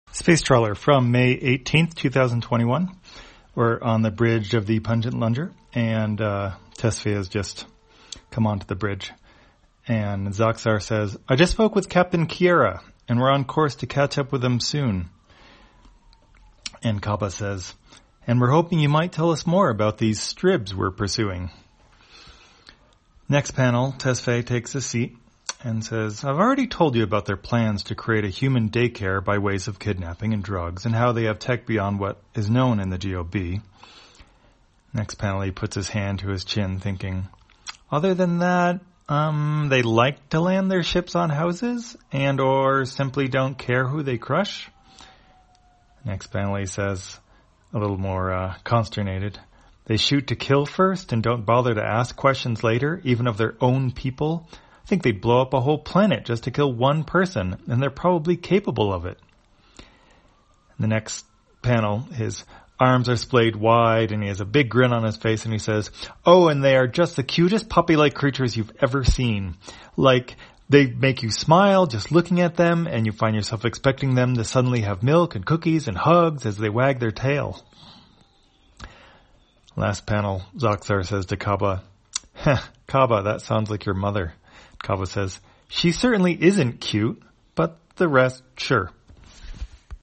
Spacetrawler, audio version For the blind or visually impaired, May 18, 2021.